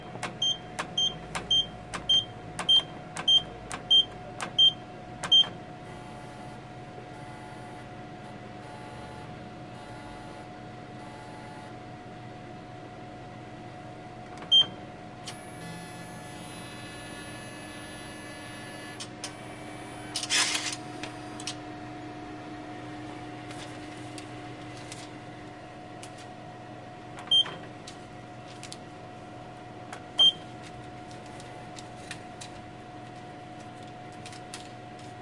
Tag: 现金 银行 货币退出 自动取款机